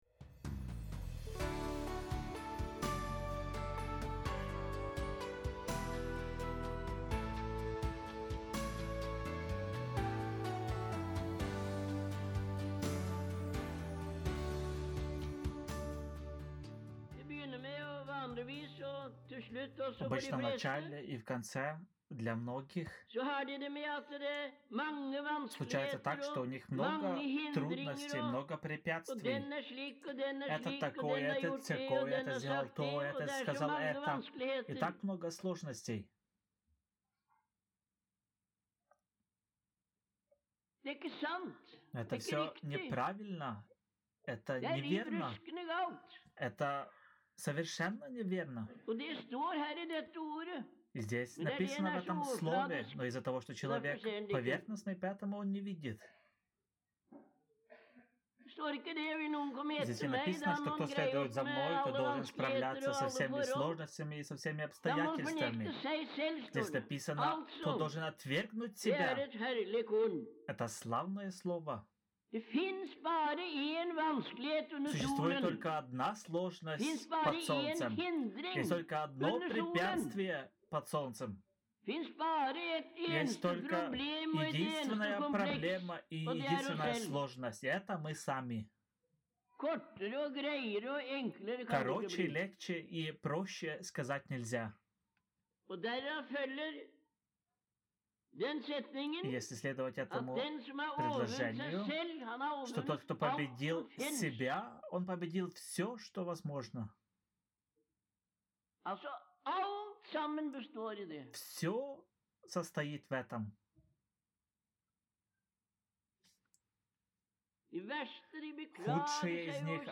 Taleutdrag fra januar 1973